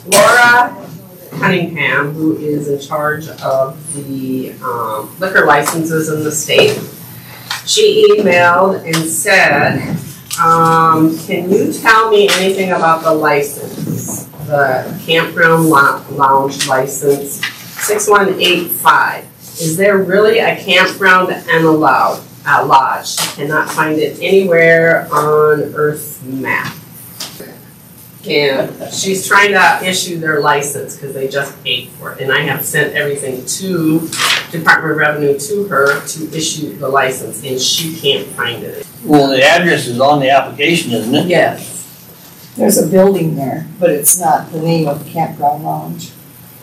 The Walworth County Commission held a regular meeting on Wednesday, Dec. 10th.
Walworth County Sheriff Josh Boll and Register of Deeds Brenda DeToy presented their monthly reports.